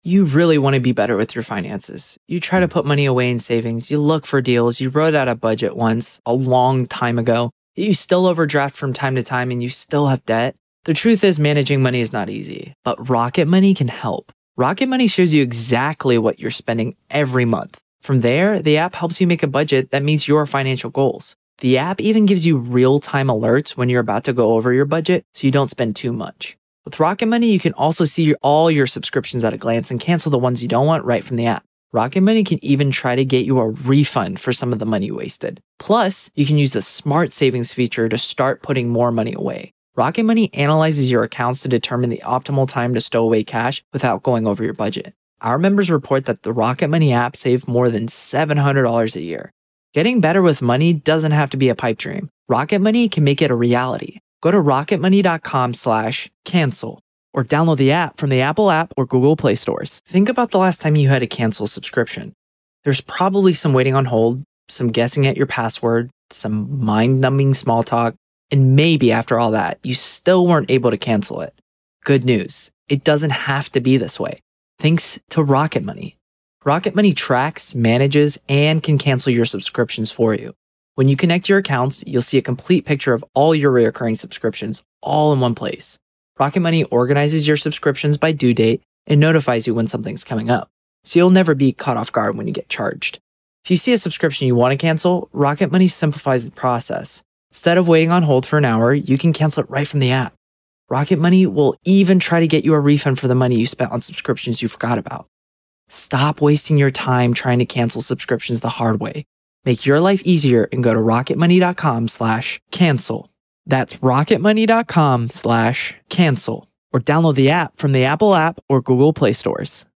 The latest Spanish news headlines in English: 17th September 2024